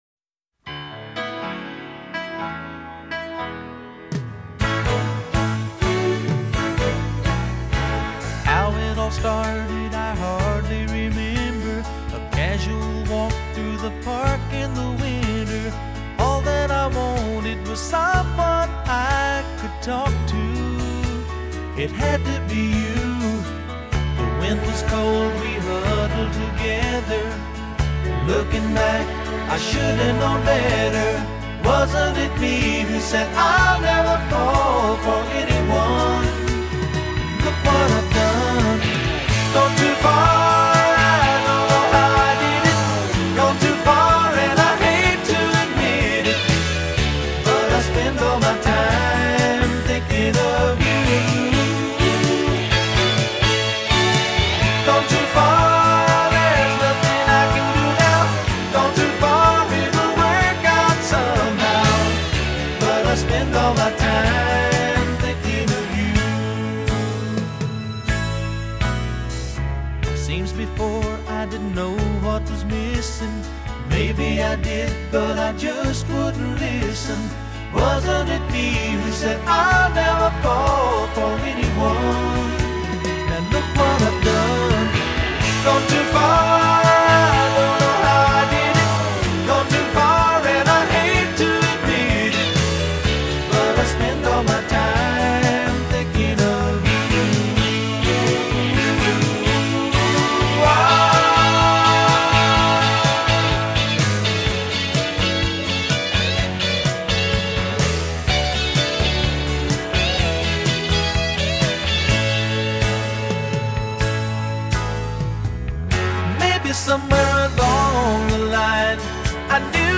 soft-serve pop